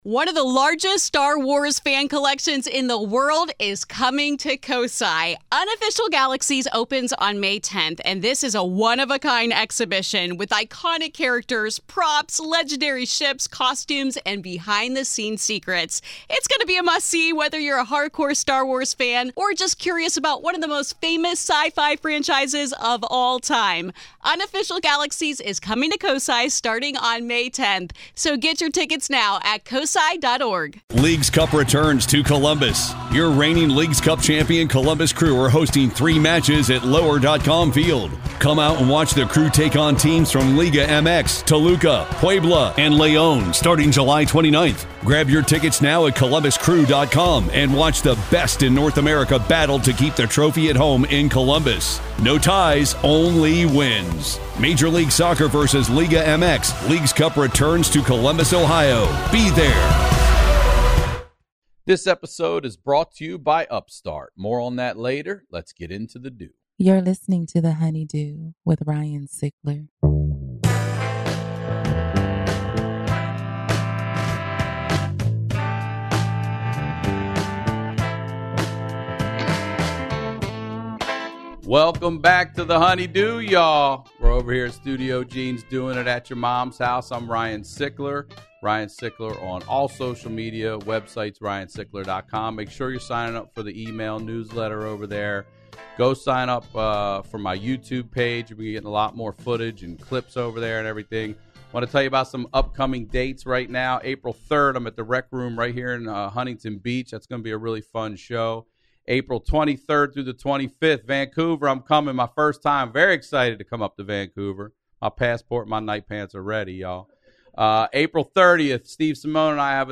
She sat down with me to talk about her rough childhood, growing up in poverty. She was raised by her grandmother, both of her parents were on drugs and abusive to each other and man does she have some stories!